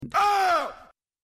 mummy yell Soundboard: Play Instant Sound Effect Button